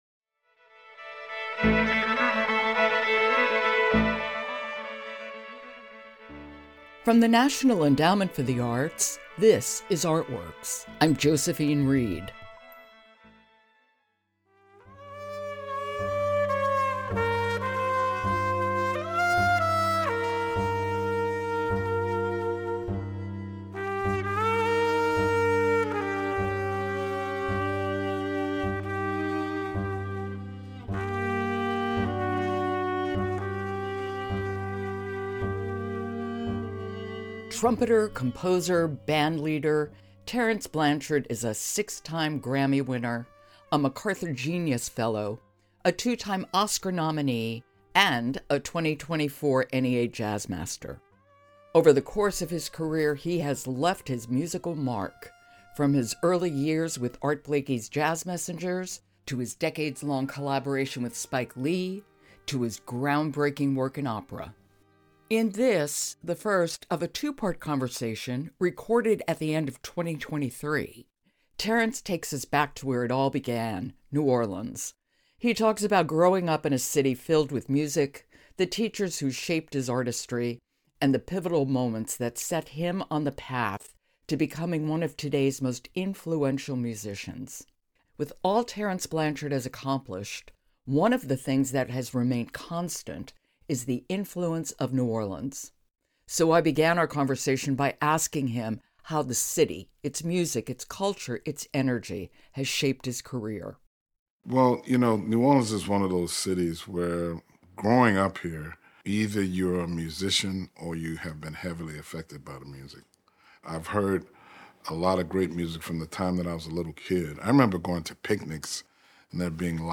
Trumpeter, composer, and 2024 NEA Jazz Master Terence Blanchard talks about his musical roots, early years, and working with Spike Lee.